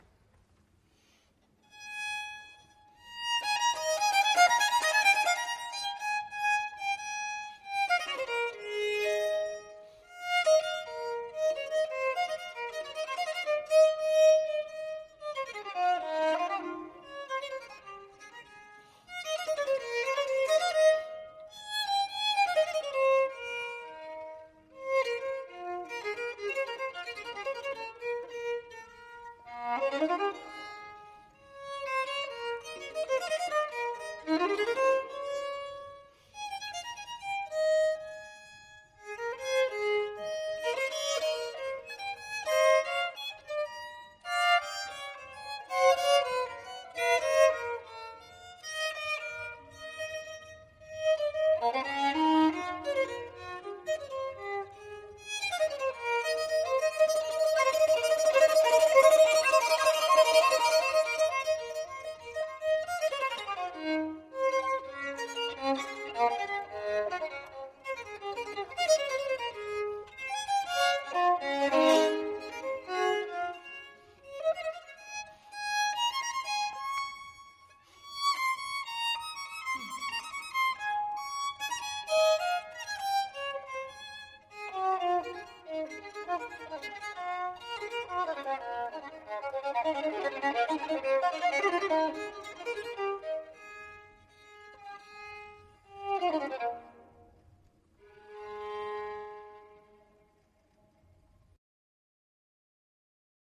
live at St Mary – at – Hill (16th January 2018)